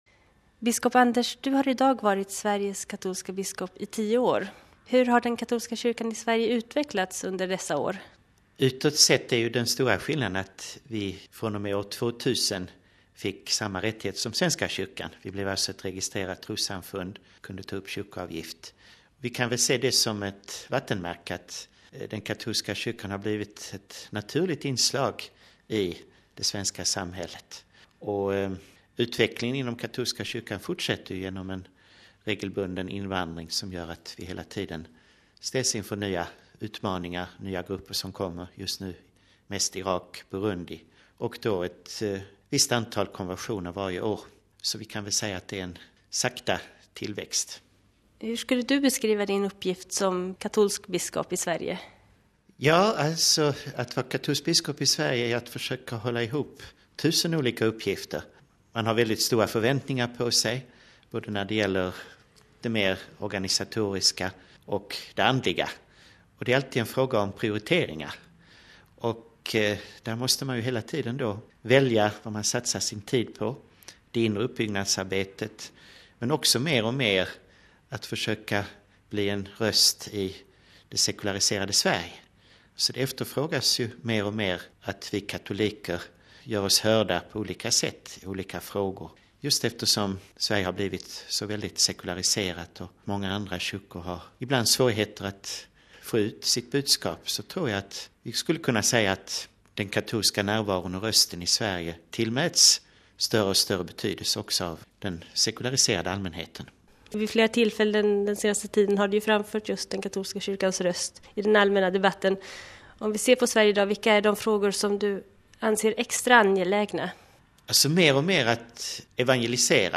I en intervju med Vatikanradion berättar han om sin uppgift och om den katolska kyrkan i Sverige.